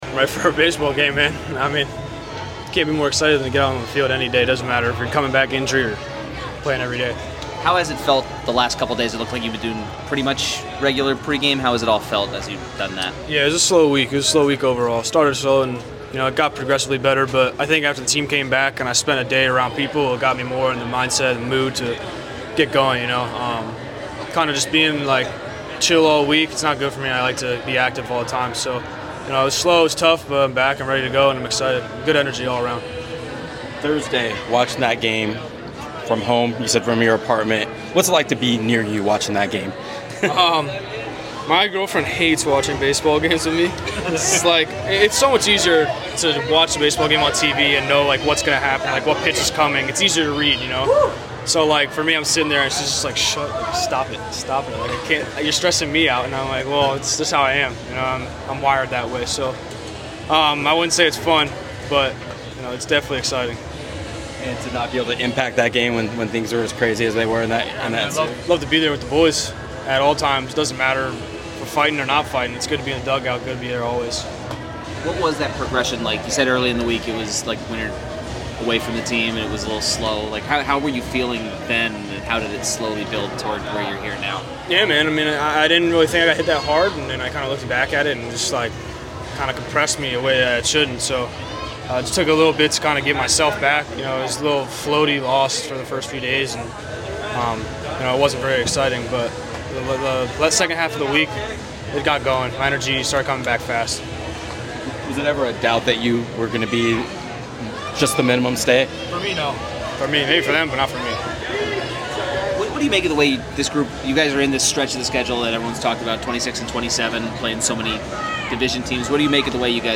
Padres centerfielder Jackson Merrill speaks to the media before he is reinstated from the 7-day injured list and returns to the Padres' lineup for Sunday's game against the Royals.